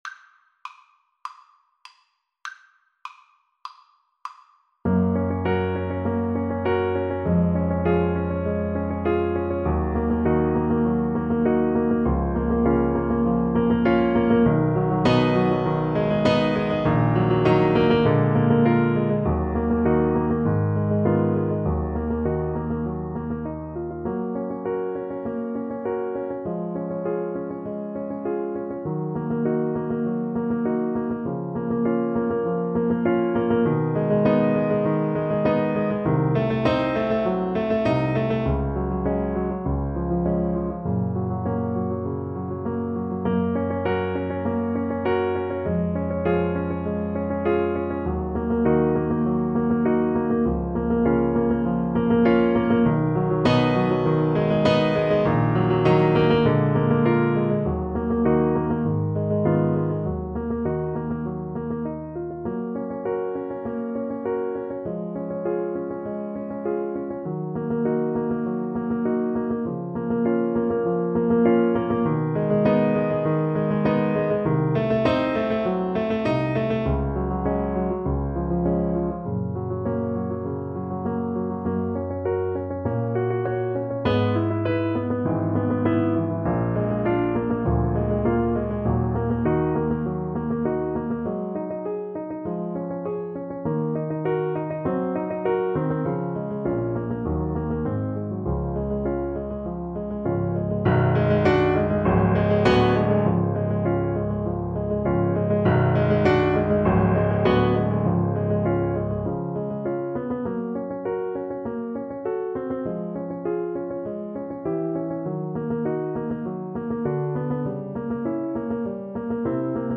Play (or use space bar on your keyboard) Pause Music Playalong - Piano Accompaniment Playalong Band Accompaniment not yet available transpose reset tempo print settings full screen
Gb major (Sounding Pitch) Eb major (Alto Saxophone in Eb) (View more Gb major Music for Saxophone )
Allegro moderato (View more music marked Allegro)
Classical (View more Classical Saxophone Music)